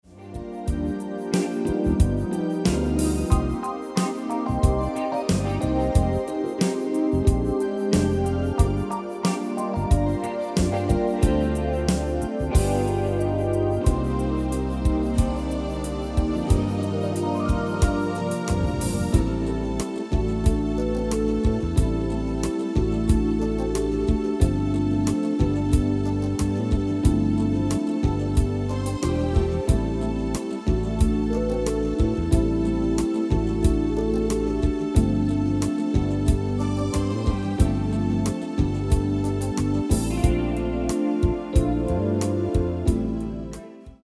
Demo/Koop midifile
Genre: Ballads & Romantisch
Toonsoort: Am/Cm
- Vocal harmony tracks